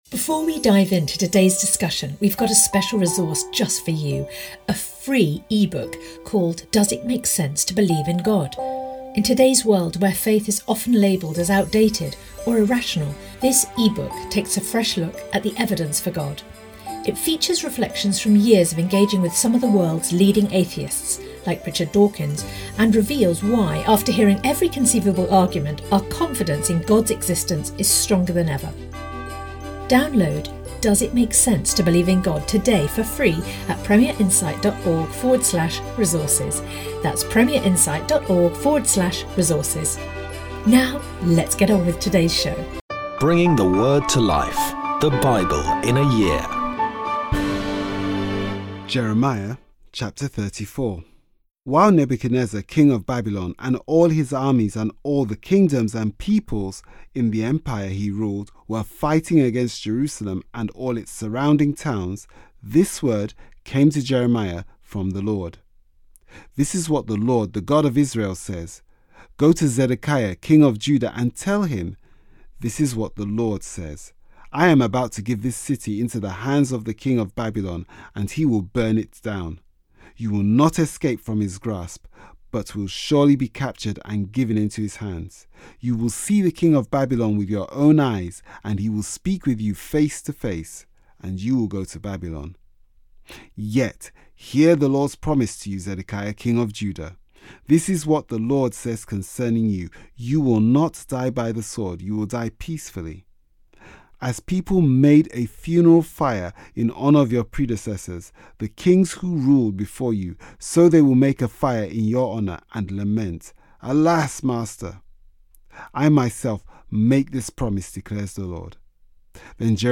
Today's readings come from Jeremiah 34-35; Hebrews 10